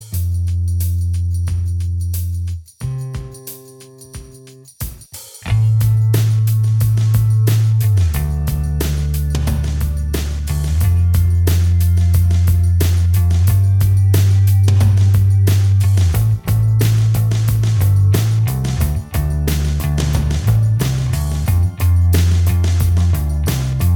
Minus Guitars Indie / Alternative 4:32 Buy £1.50